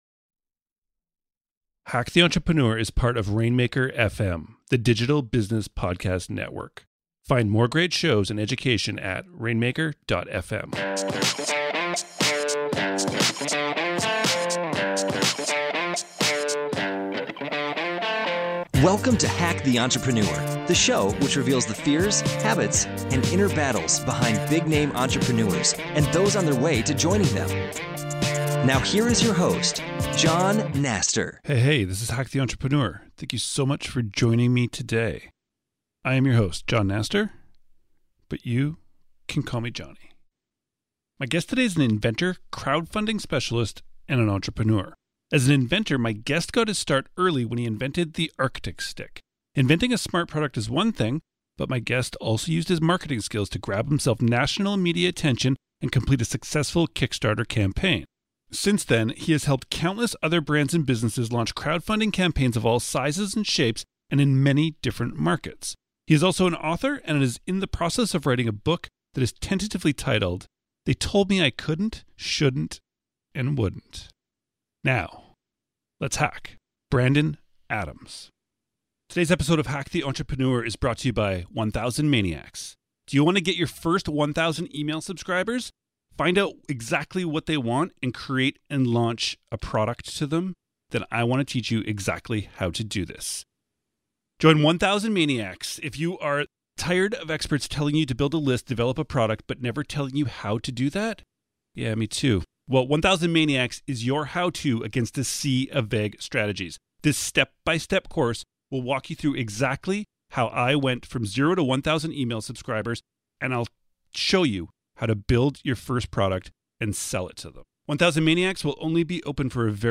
My guest today is an inventor, crowdfunding specialist, and entrepreneur.